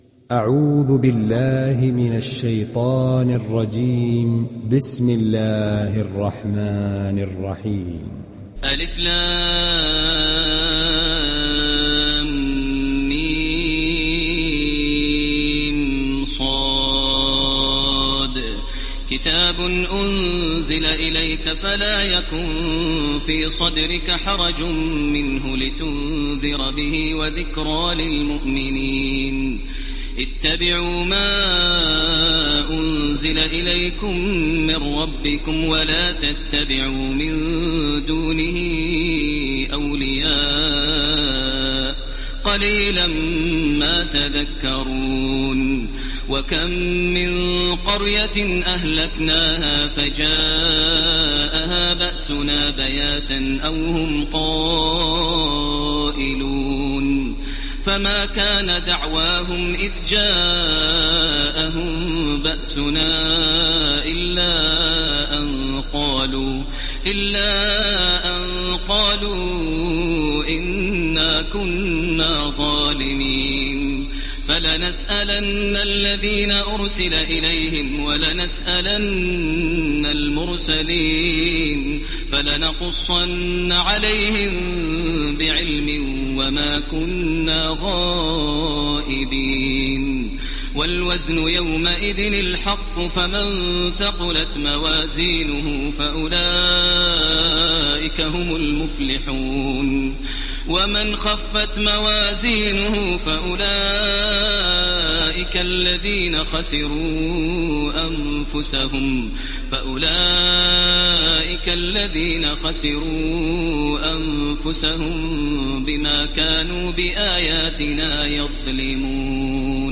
Araf Suresi İndir mp3 Taraweeh Makkah 1430 Riwayat Hafs an Asim, Kurani indirin ve mp3 tam doğrudan bağlantılar dinle
İndir Araf Suresi Taraweeh Makkah 1430